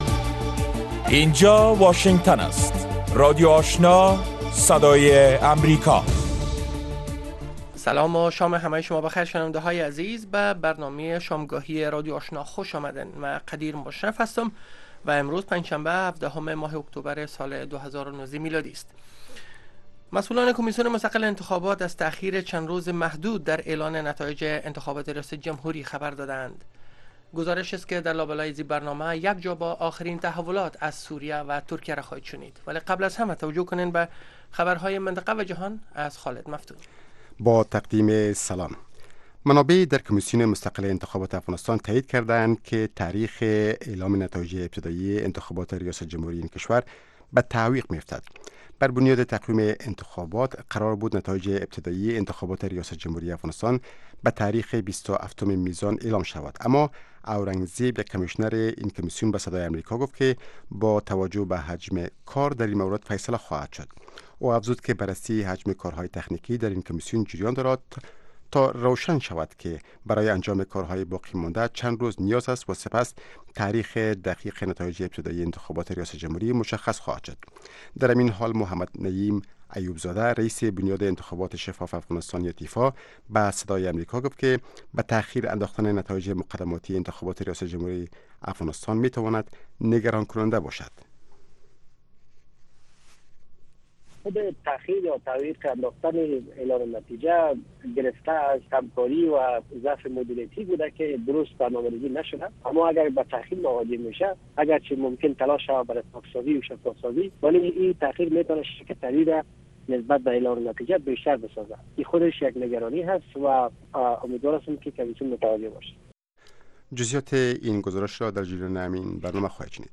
در برنامه خبری شامگاهی، خبرهای تازه و گزارش های دقیق از سرتاسر افغانستان، منطقه و جهان فقط در سی دقیقه پیشکش می شود.